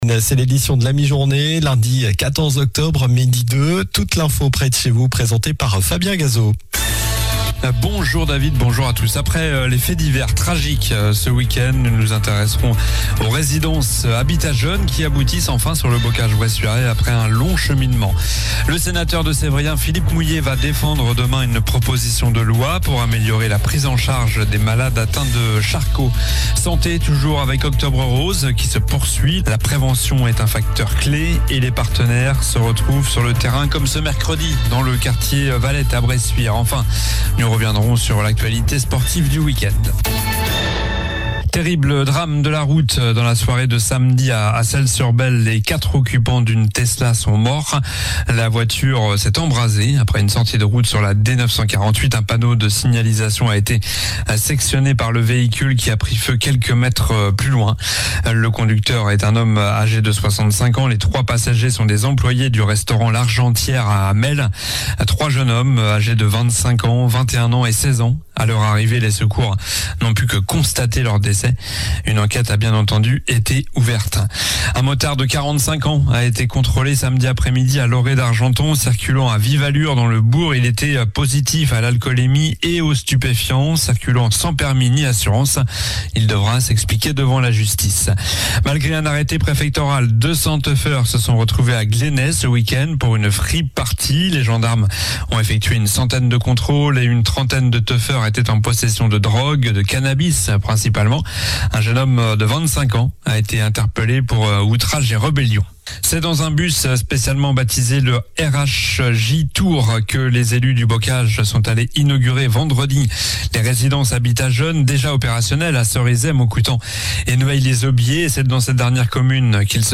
Journal du lundi 14 octobre (midi)